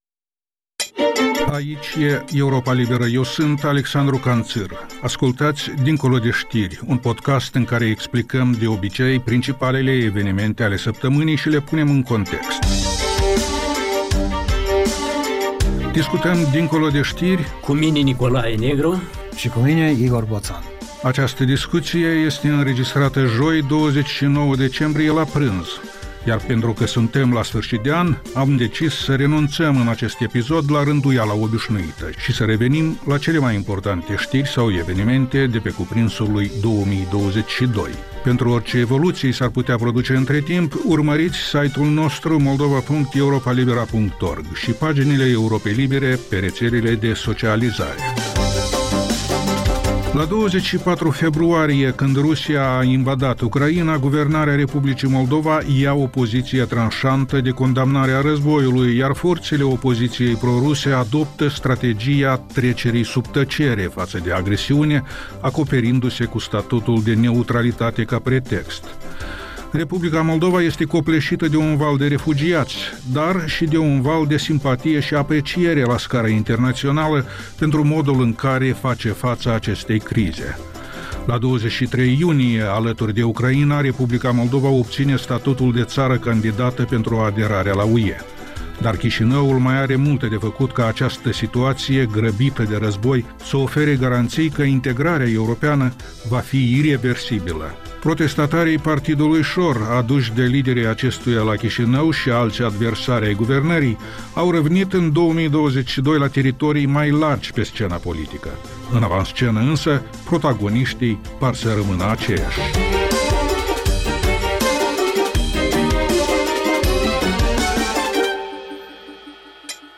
Ştiri, interviuri, analize. Programul care stabileşte agenda zilei.